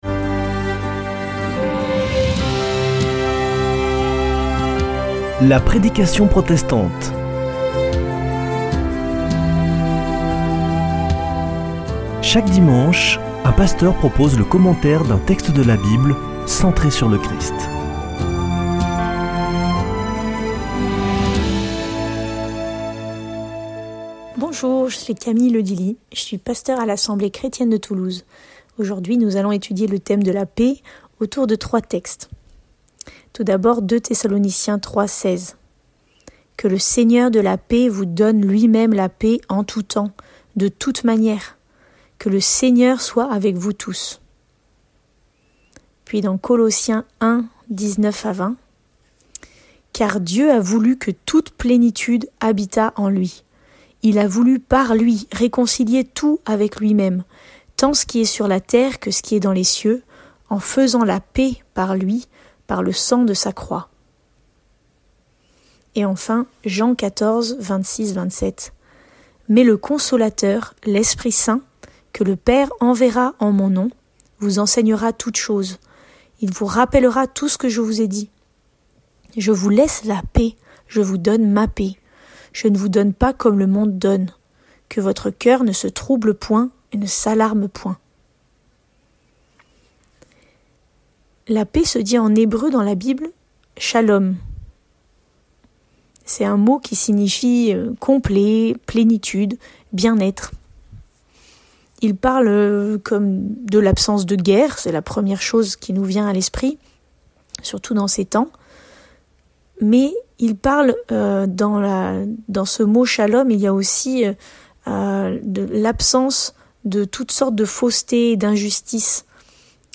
La prédication protestante
Une émission présentée par Des protestants de la région